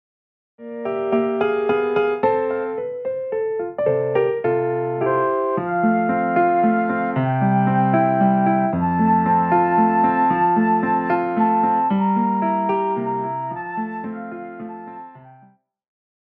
Classical
Vocal - female,Vocal - male
Piano
Instrumental
Voice with accompaniment
It is passionate and expressive.
This is the original version for voice and piano.